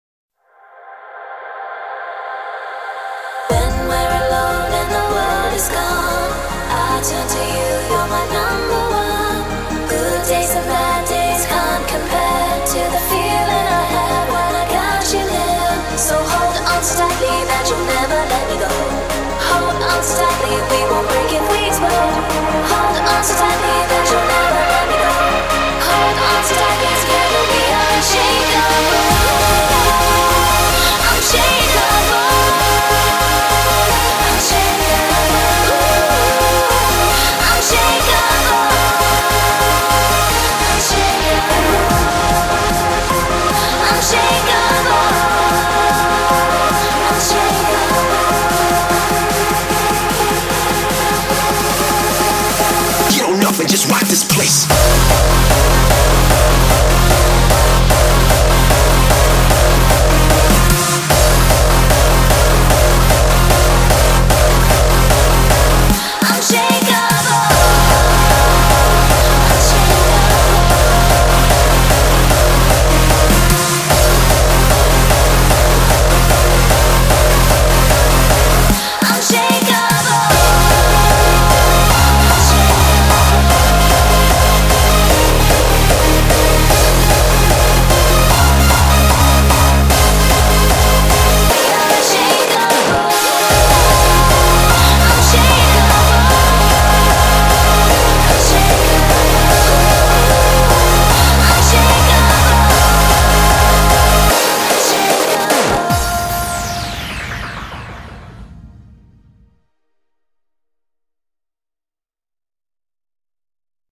BPM150
Audio QualityPerfect (High Quality)
Nice vocal hardstyle song